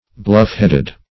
Search Result for " bluff-headed" : The Collaborative International Dictionary of English v.0.48: Bluff-headed \Bluff"-head`ed\, a. (Naut.)